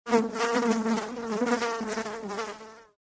Звуки мухи
Звук жужжания мухи 4